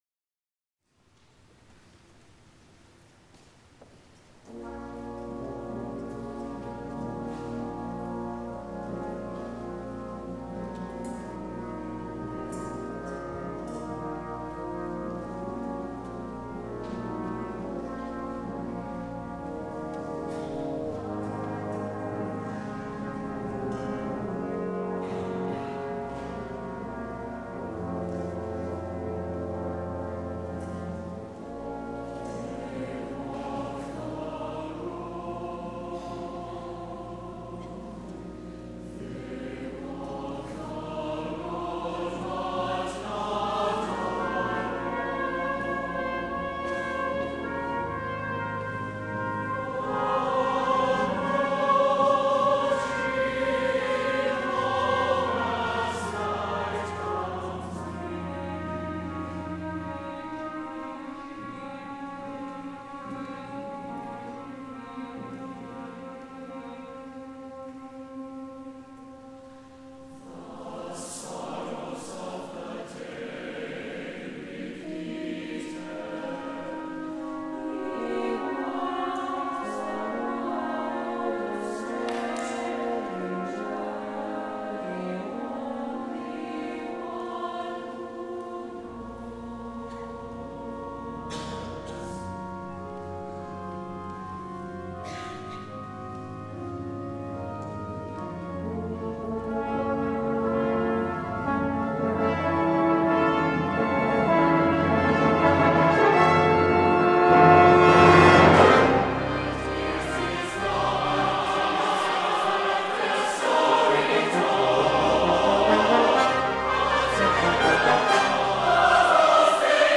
SATB, organ, 2 trumpets, 2 trombones, and timpani